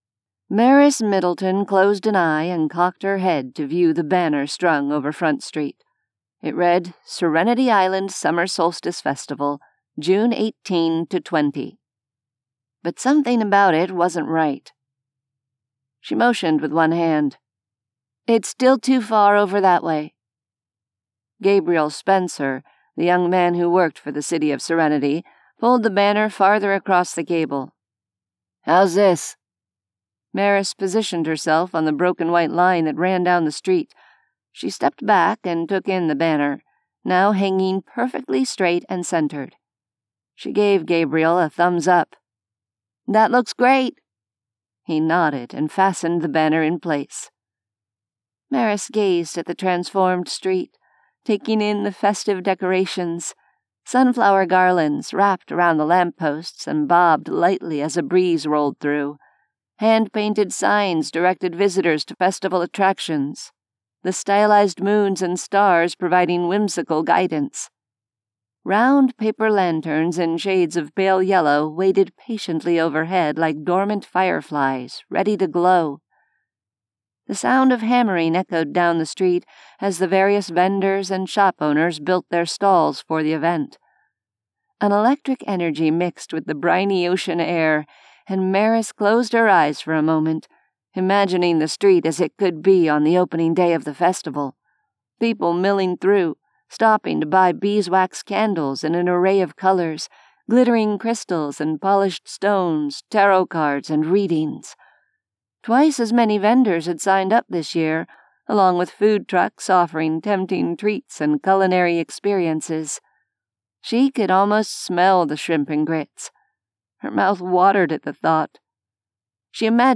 A sampling of audiobooks